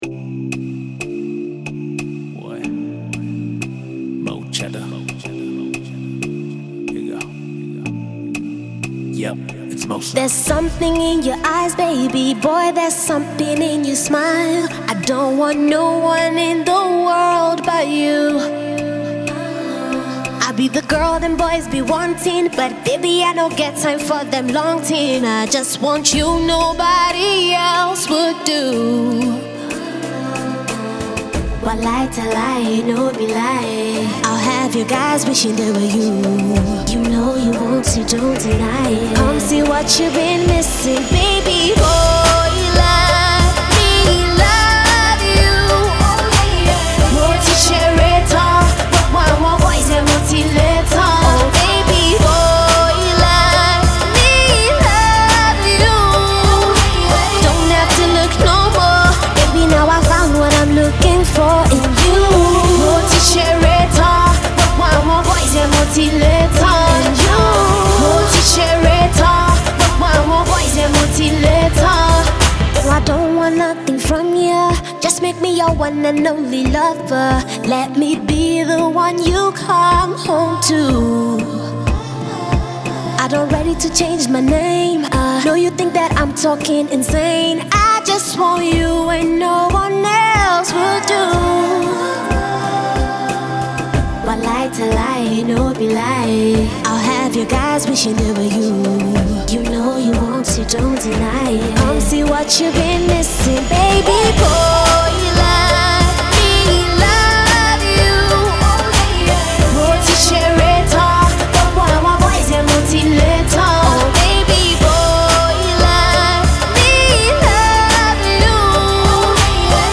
effervescent and eclectic style